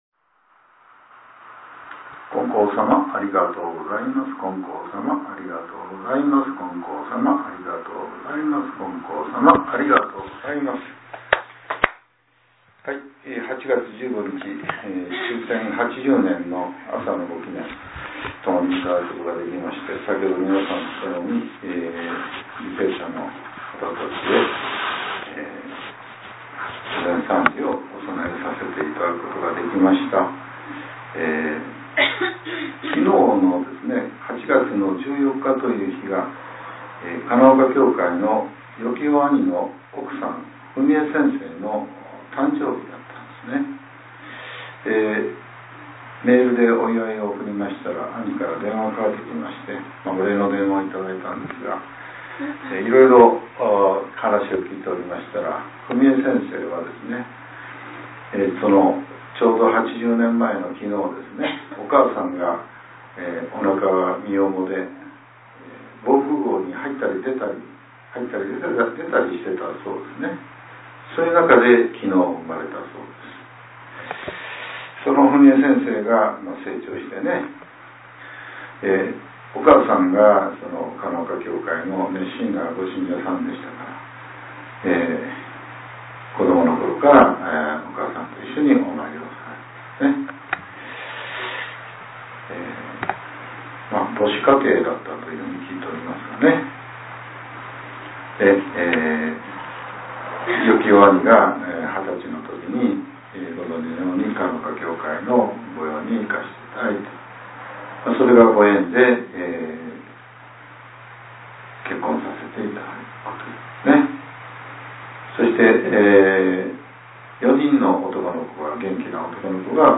令和７年８月１５日（朝）のお話が、音声ブログとして更新させれています。